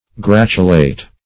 Gratulate \Grat"u*late\, a.